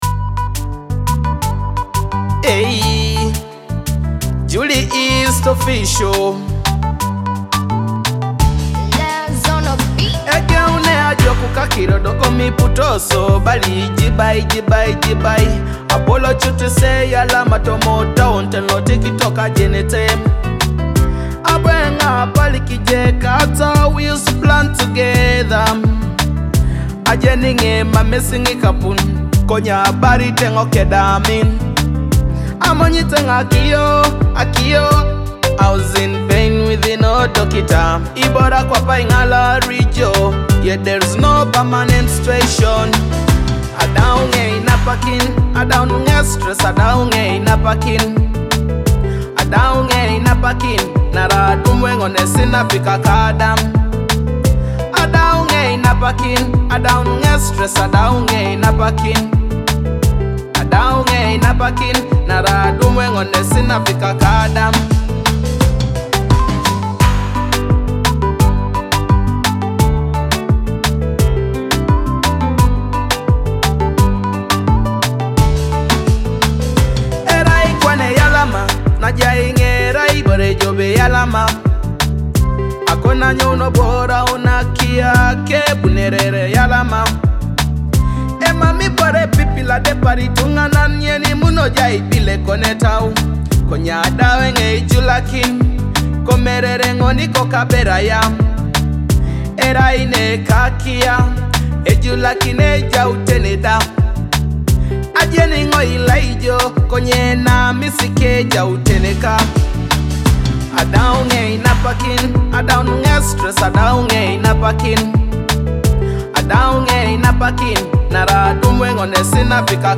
smooth vocals
rich instrumental arrangement